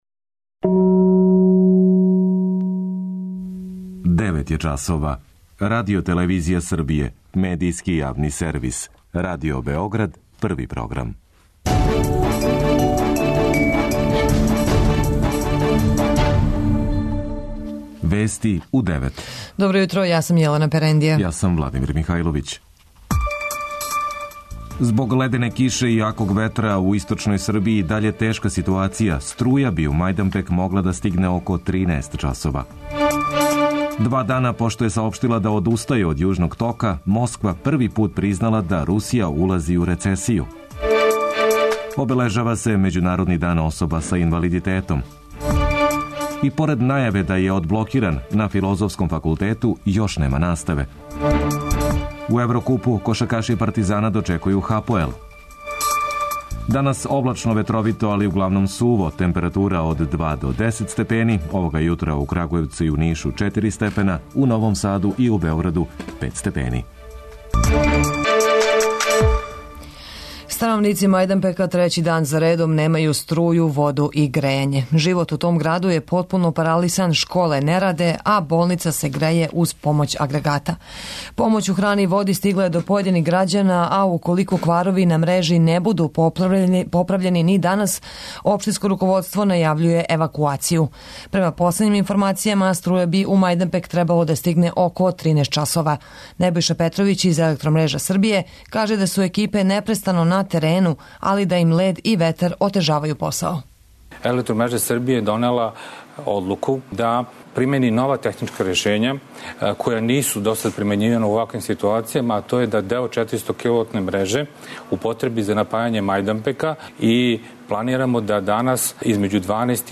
преузми : 10.12 MB Вести у 9 Autor: разни аутори Преглед најважнијиx информација из земље из света.